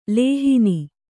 ♪ lēhini